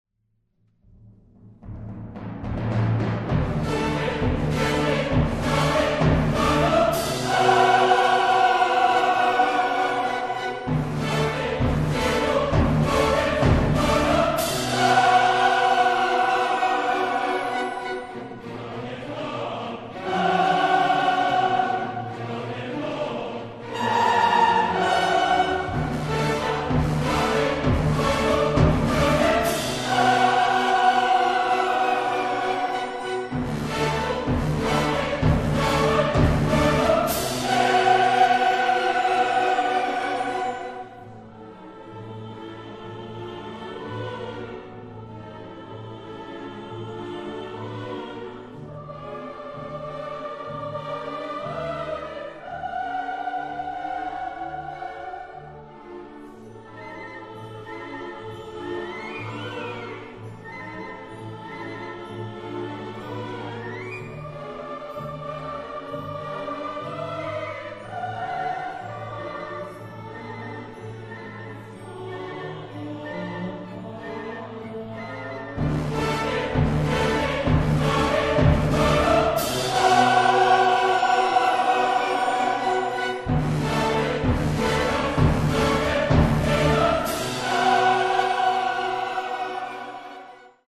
La obra orquestal y operística de Borodin, importante compositor ruso del siglo XIX, causa impresión espectacular, es brillante e irresistiblemente bárbara al estilo del exotismo de la música romántica.